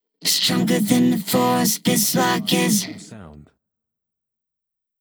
Can also be used as a car sound and works as a Tesla LockChime sound for the Boombox.